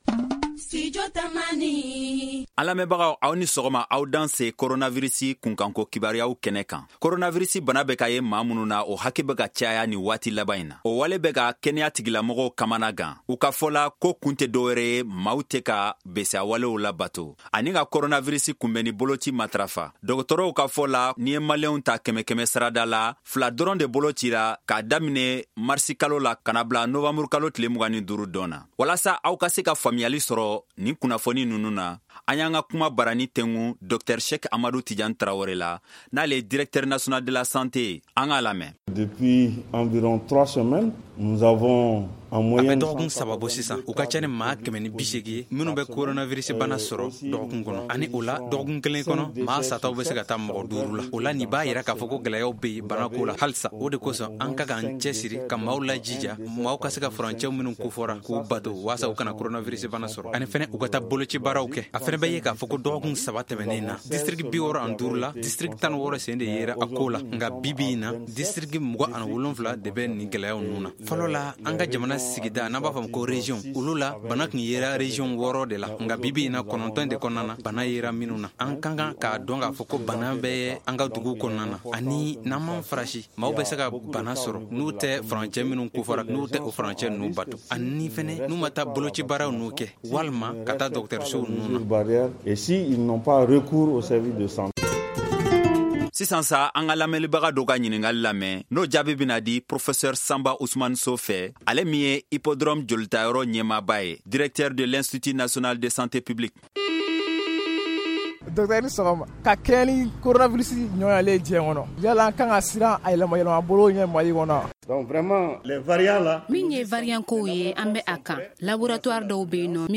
Pour en savoir plus, nous avons tendu notre micro à Dr Cheikh Amadou Tidiane Traoré, Directeur national de la santé publique et de l’Hygiène Publique.